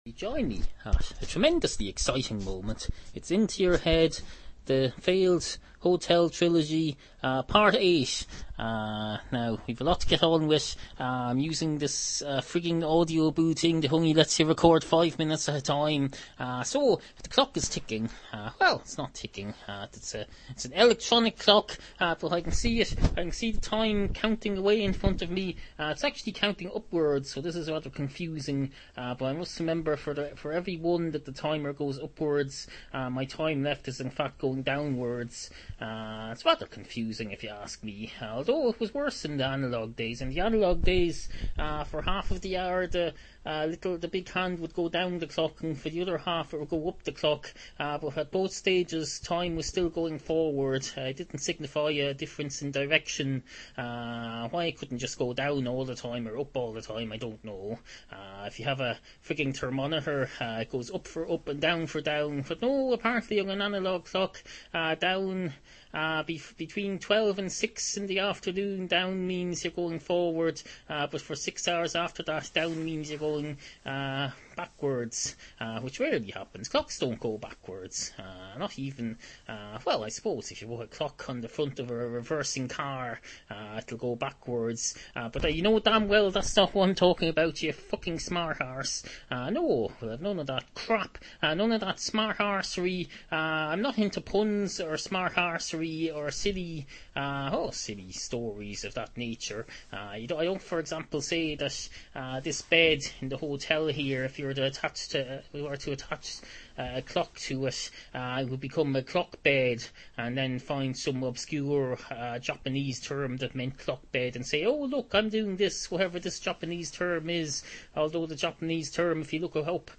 New listeners are reeuested to start with later episodes, like the 500s or, even better, the 800s. 2nd Hotel Trilogy – Parts VIII through X Excuse the variable sound quality, but here are more recent recordings from the comfort of a hotel room somewhere in Ireland.